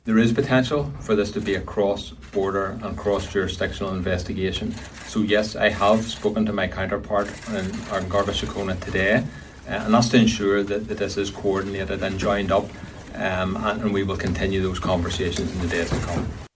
PSNI Assistant Chief Constable Davy Beck says they’re also in touch with Gardaí about the inquiry: